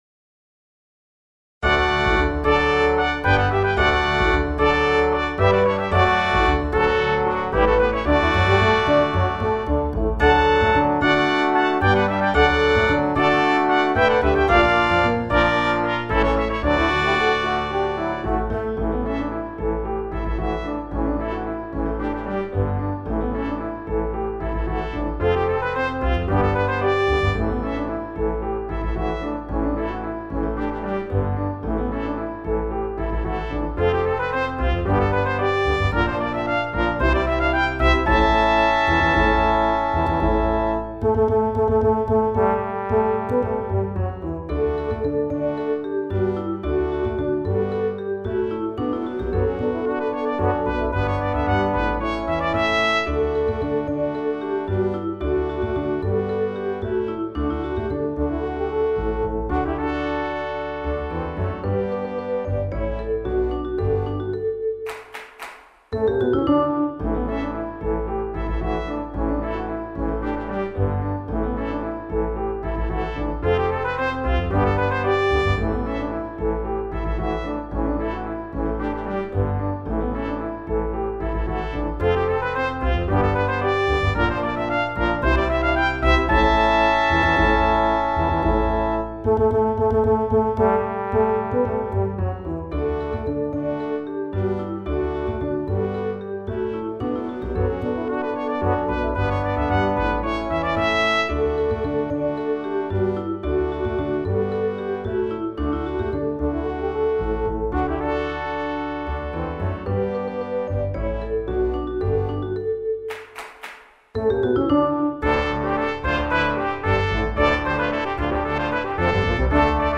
Chorale d'Enfants (10 à 11 ans) et Quintette de Cu